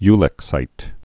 (ylĭk-sīt, y-lĕk-)